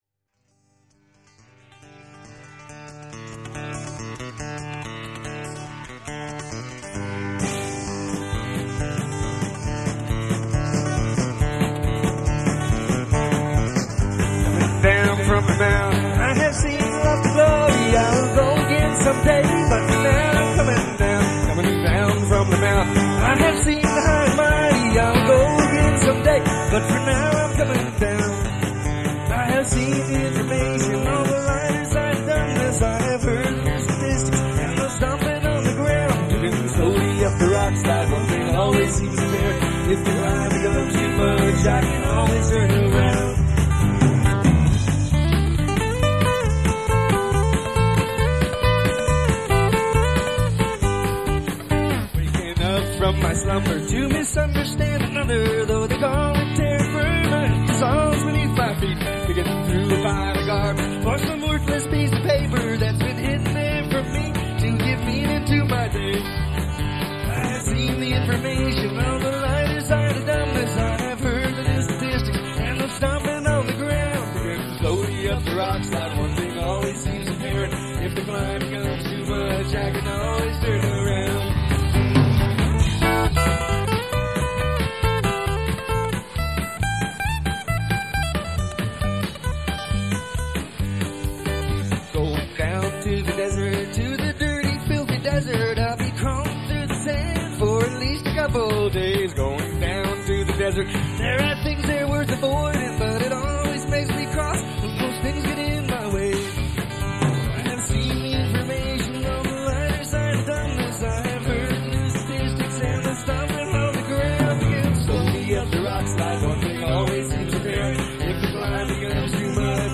Slims, San Francisco, CA